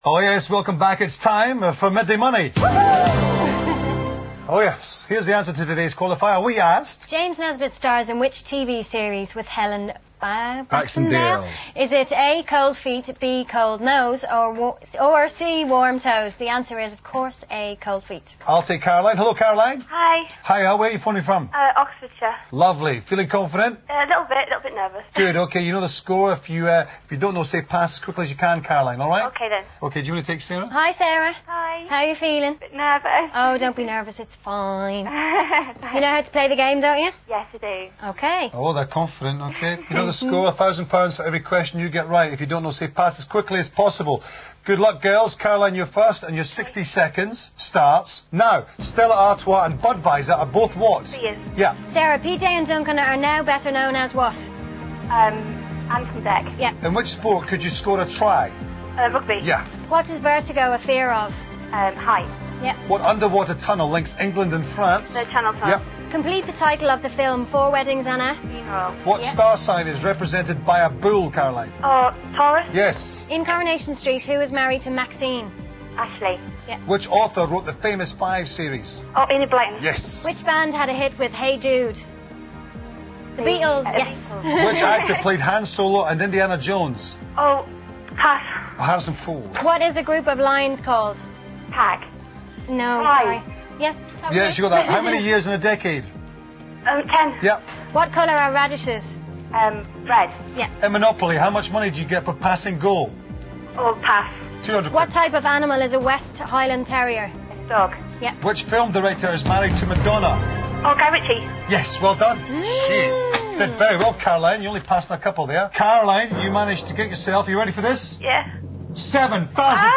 All crackling/rustling is from the microphones rubbing on clothing.